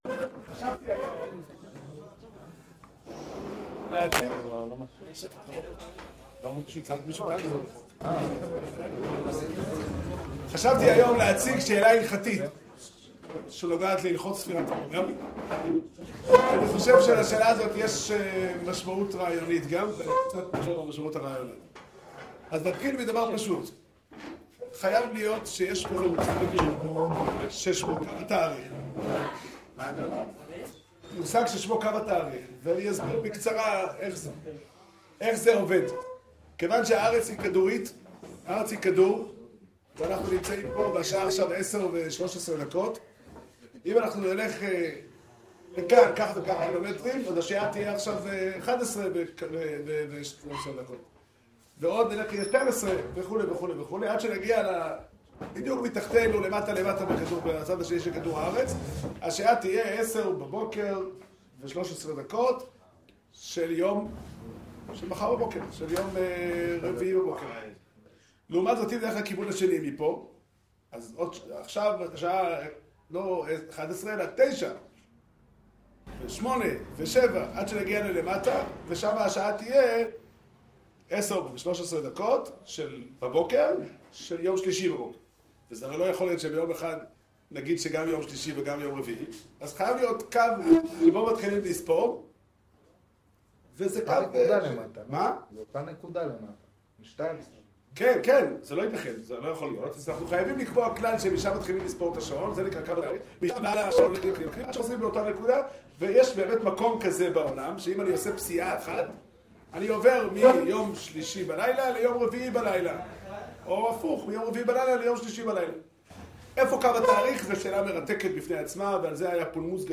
שיעור שנמסר בישיבת דרך ה' בתאריך ט"ז אייר תשע"ו